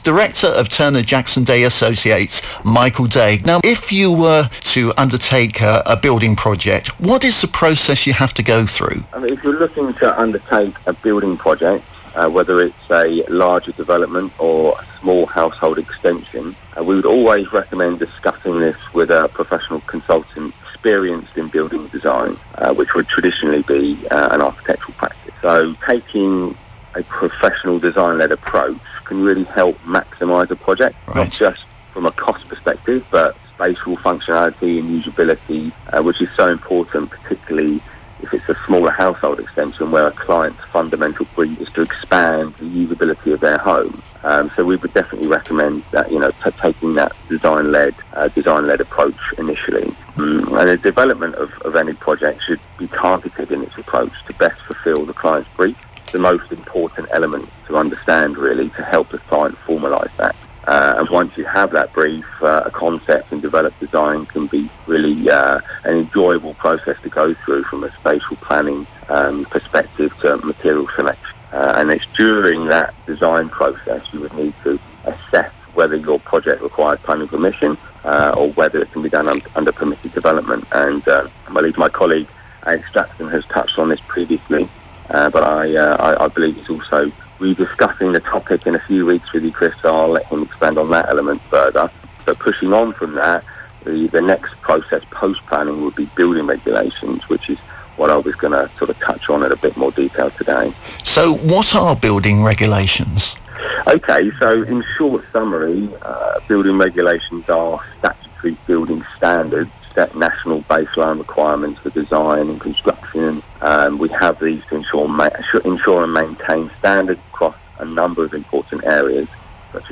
Building Regulations. Radio interview